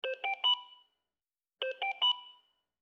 Здесь вы можете слушать и загружать сигналы, сопровождающие измерение уровня кислорода и пульса.
Звуки пульсоксиметра: как прибор сигнализирует о снижении частоты сердцебиения